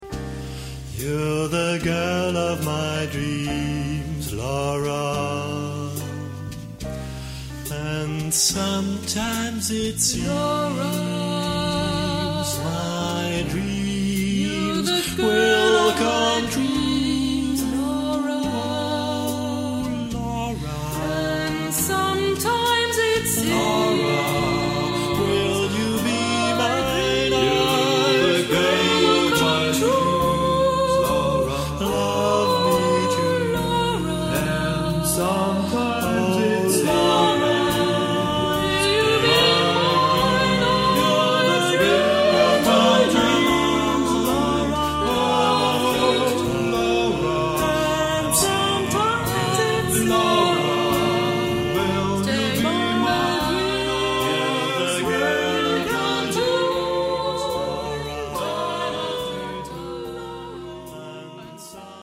Genre-Style-Form: Secular ; Canon ; ballad
Type of Choir: unspecified voicing  (4 unspecified voices )
Tonality: C major